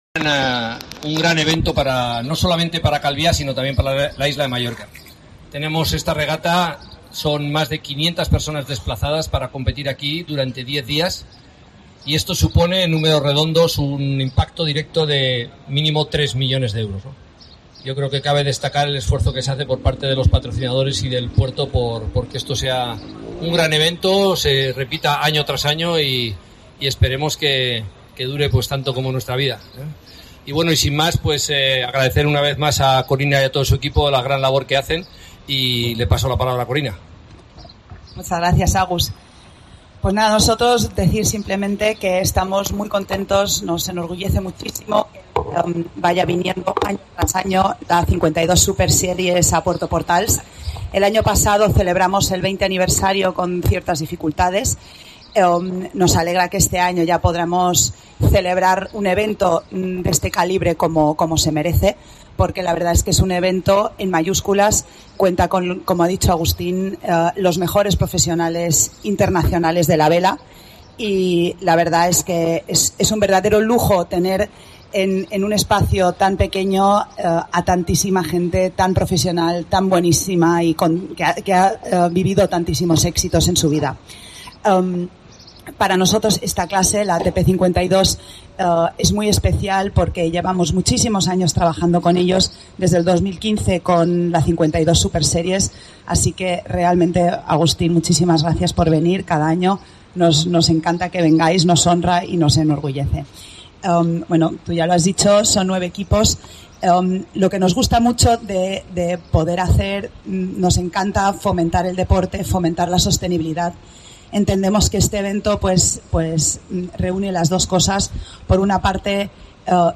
Presentación de las 52 Súper Series en Portals